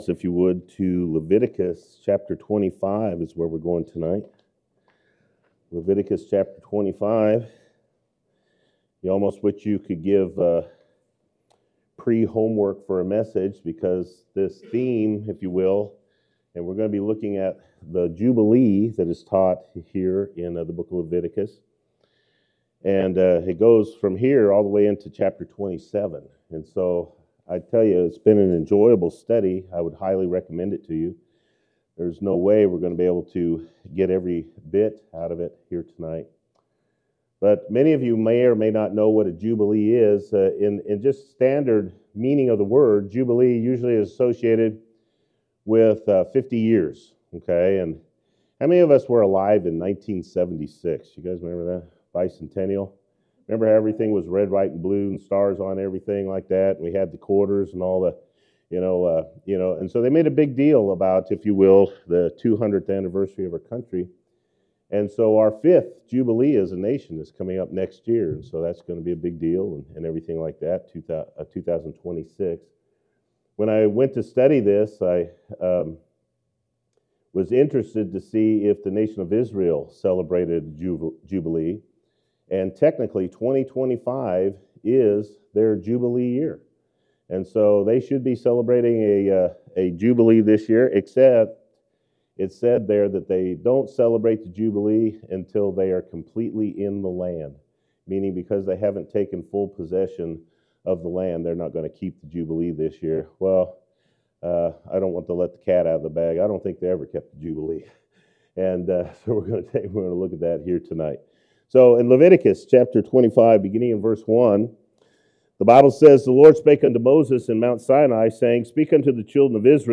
Sermons | Plack Road Baptist Church